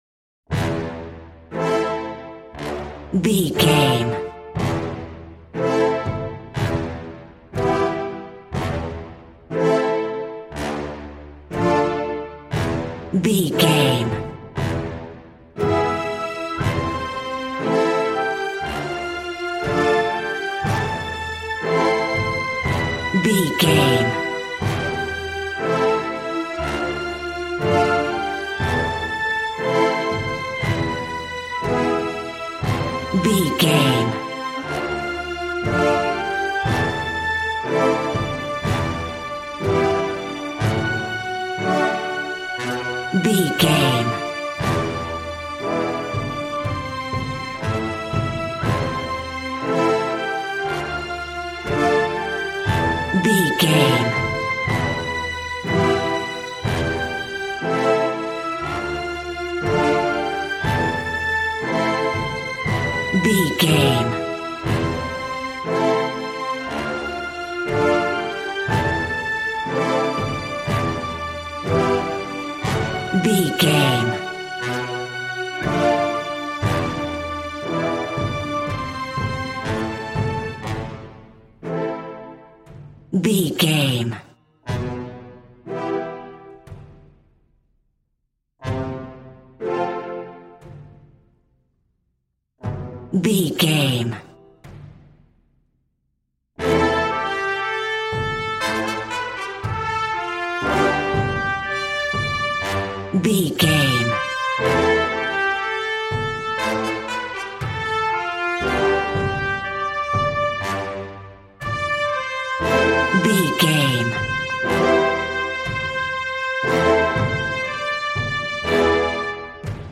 Valiant and Triumphant music for Knights and Vikings.
Regal and romantic, a classy piece of classical music.
Aeolian/Minor
brass
strings
violin
regal